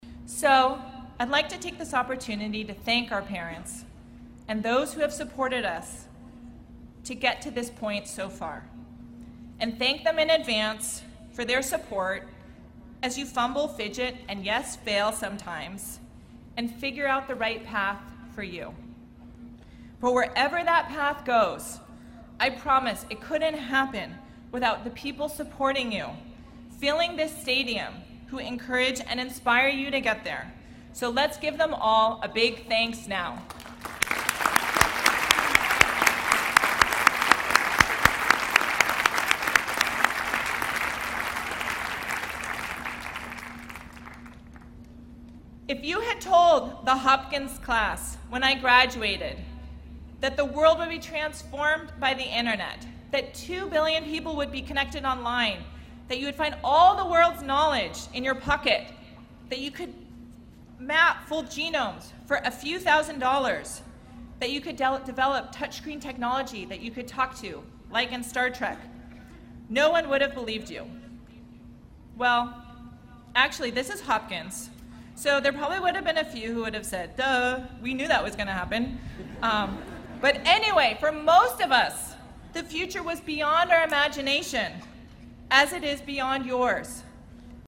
公众人物毕业演讲 第201期:苏珊沃西基2014在约翰霍普金斯大学(14) 听力文件下载—在线英语听力室